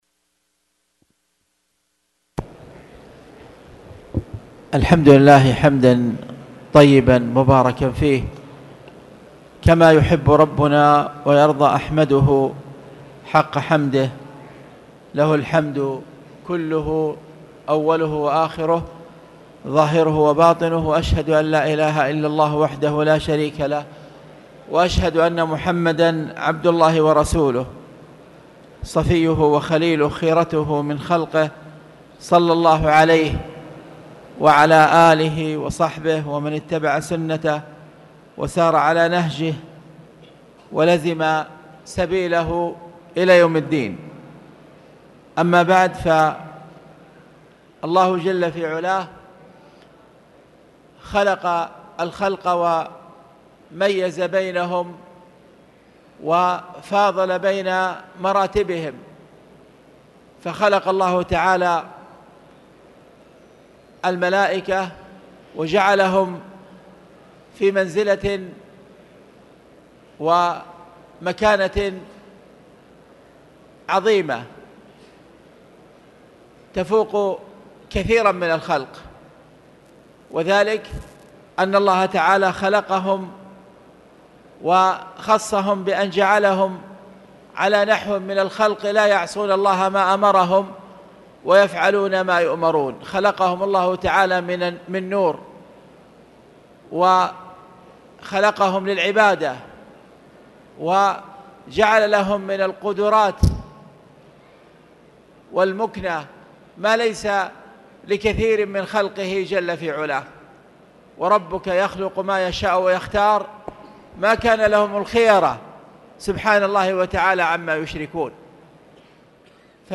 تاريخ النشر ١ ربيع الثاني ١٤٣٨ هـ المكان: المسجد الحرام الشيخ